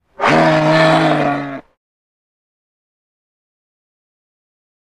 Moose Grunt, Single. Close Perspective.